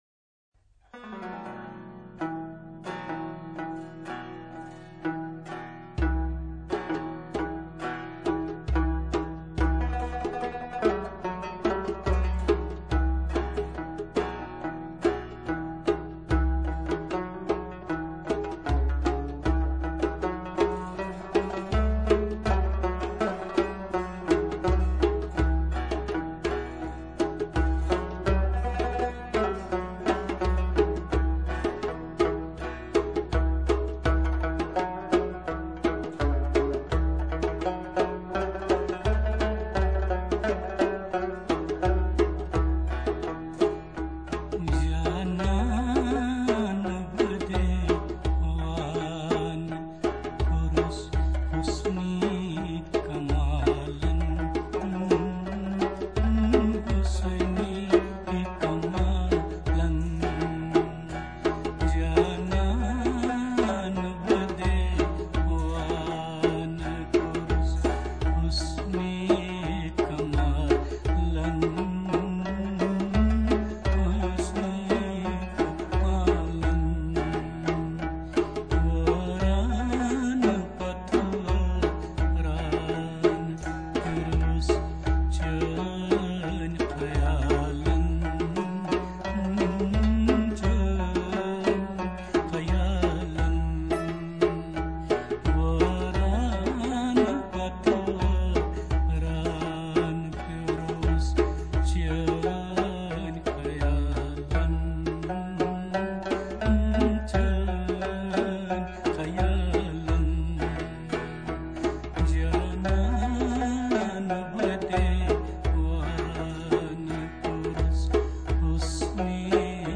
闻名国际的拨弦雷巴布
雷巴布（Rebab），一种独特而洋溢中古情调的拨弦器乐，琴声细致悠扬，韵底深藏情感，宛如溪涧清唱。
另有高脚鼓伴奏，以高度挥洒的即兴演奏技巧，完全展演出克什米尔的拉格、嘎杂尔音乐精华。